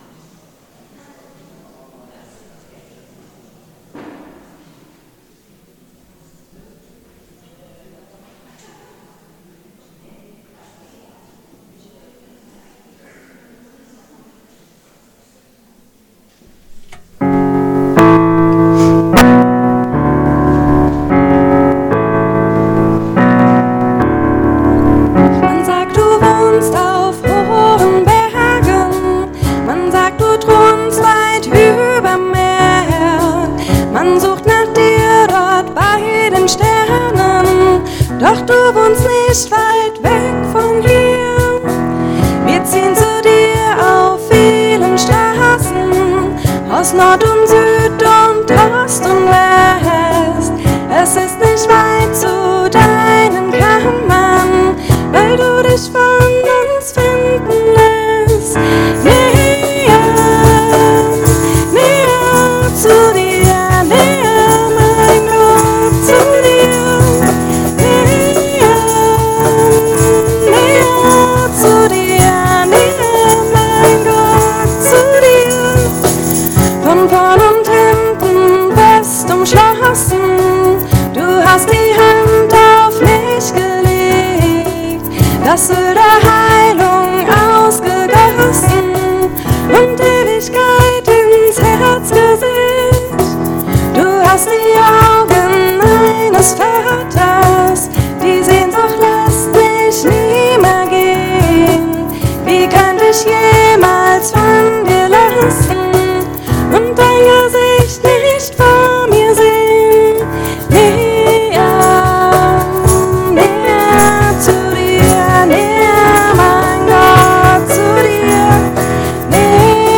Liebe Gemeinde, herzlich willkommen zum Gottesdienst zur Tauferinnerung in der Martinskirche Nierstein als Audio-Podcast